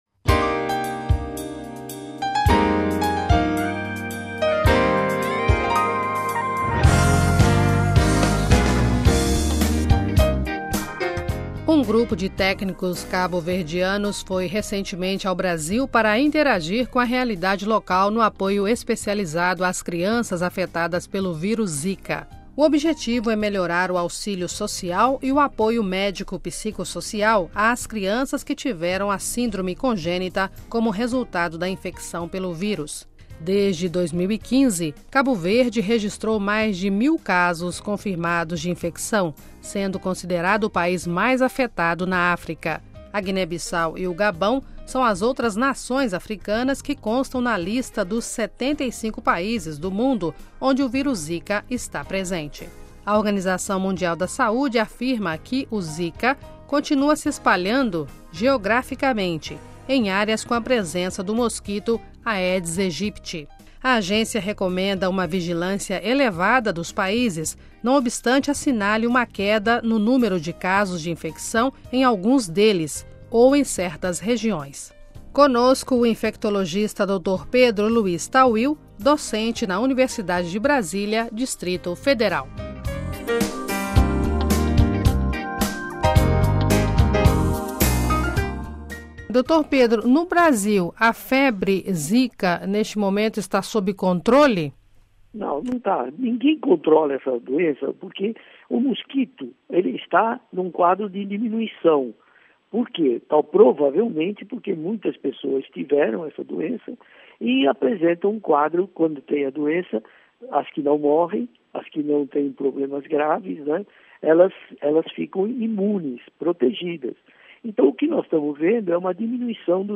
Conosco o infectologista